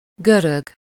Ääntäminen
Vaihtoehtoiset kirjoitusmuodot (vanhentunut) Græc Ääntäminen Tuntematon aksentti: IPA: /ɡʁɛk/ IPA: /gʁɛk/ Haettu sana löytyi näillä lähdekielillä: ranska Käännös Ääninäyte Substantiivit 1. görög Suku: m .